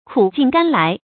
kǔ jìn gān lái
苦尽甘来发音
成语正音尽，不能读作“jǐn”。